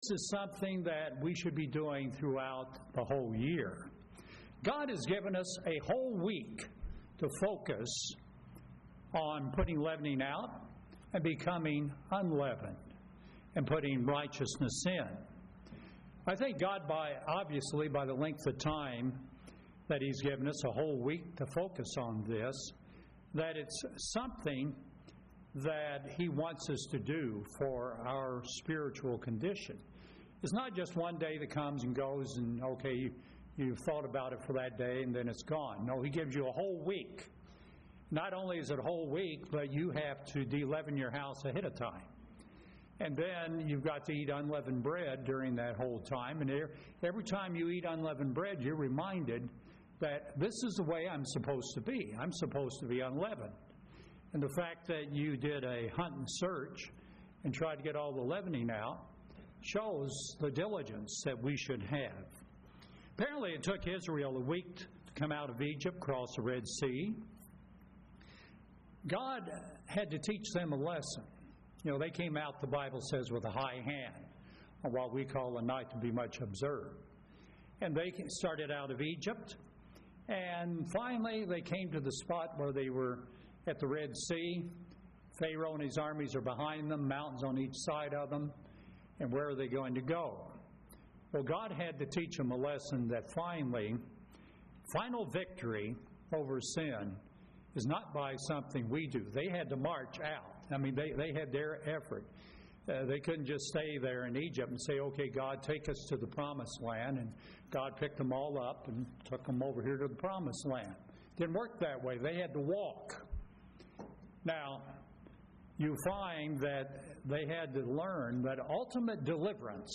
Given in Fargo, ND